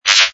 ui_shipview_select.wav